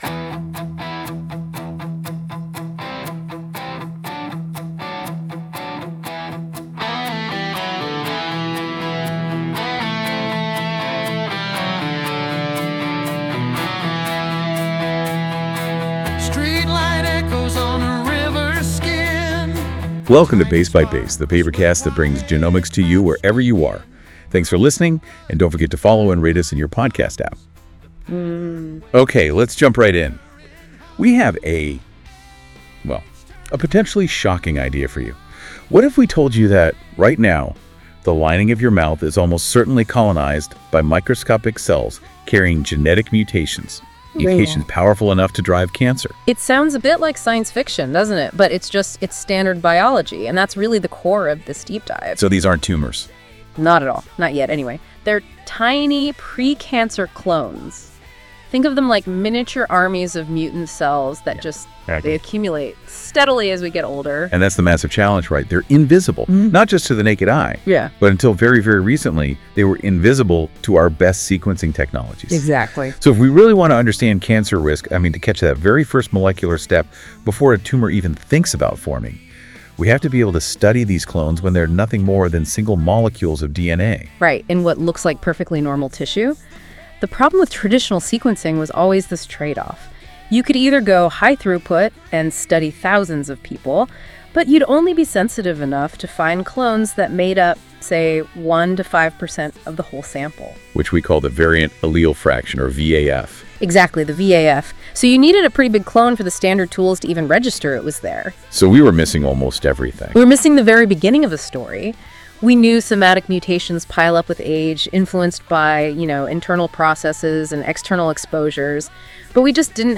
Somatic Mutation and Selection at Population Scale Music:Enjoy the music based on this article at the end of the episode.